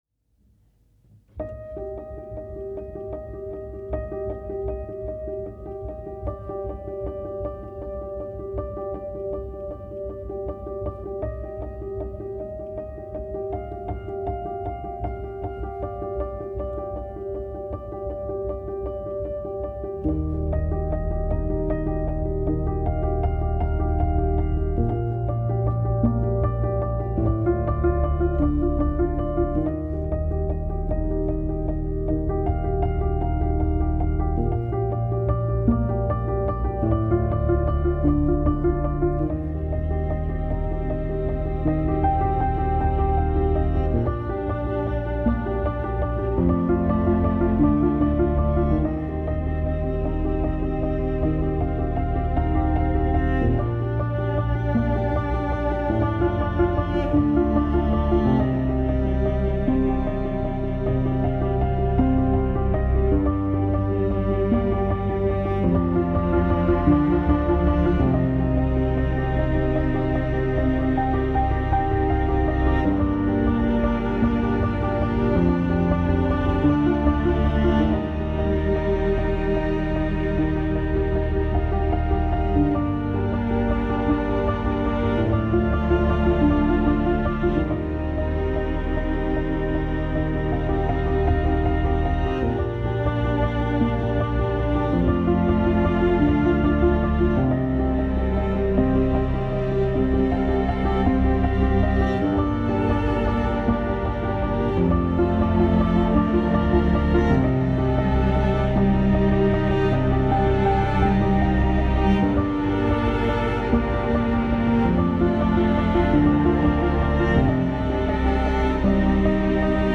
Ambient new age